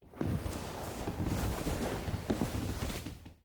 anonBedRustle.ogg